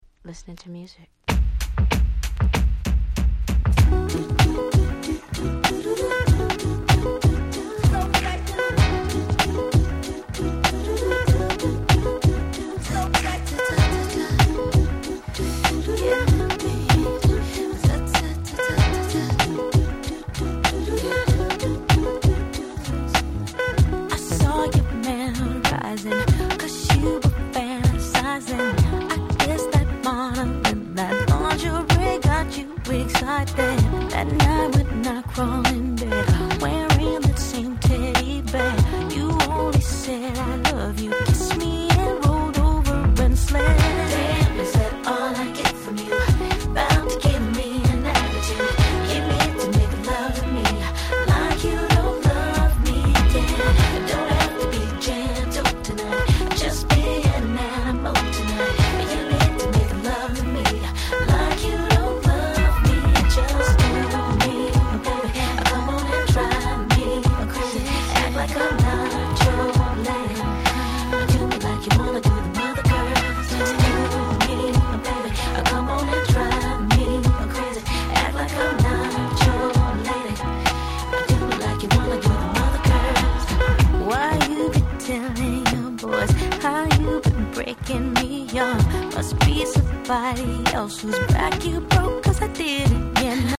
話はそれましたが、本作も所々Neo Soul風味を感じさせる良曲がちらほら。